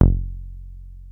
303 F#1 3.wav